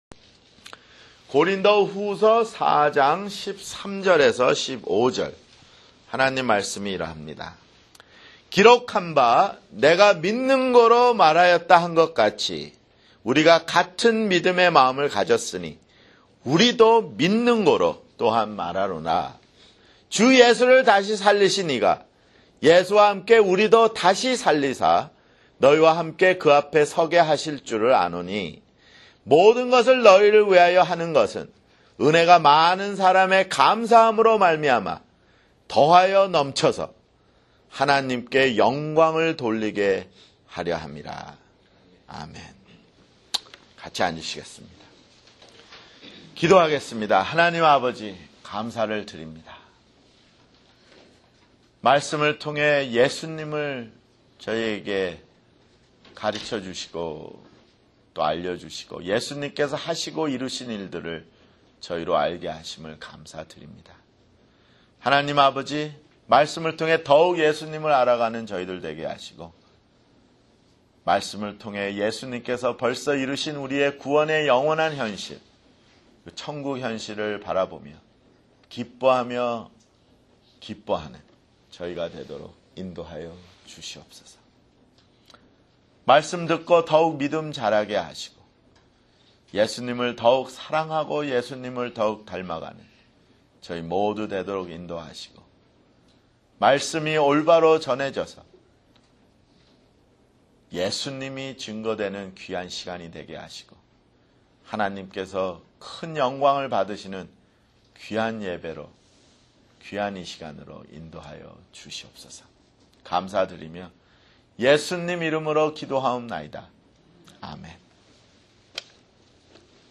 [주일설교] 고린도후서 (23)